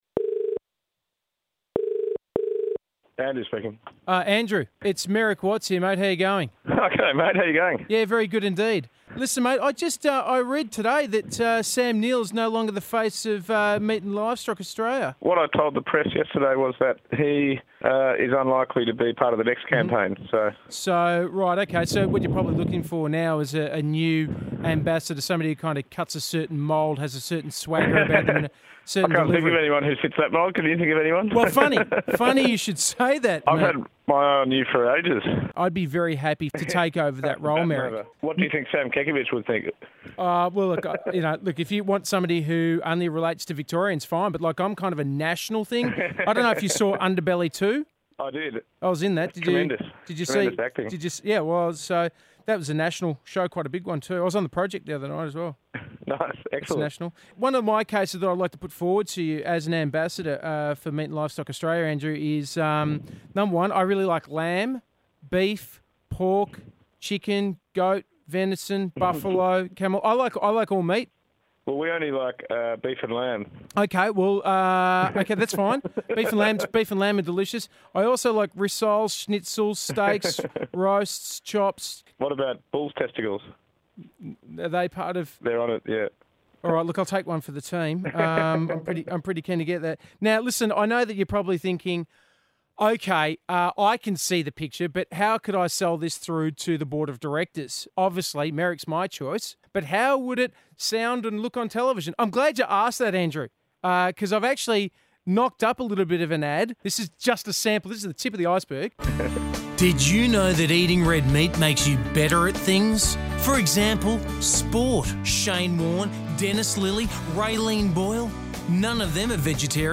GRAB-Merrick-phones-Livestock-Australia.mp3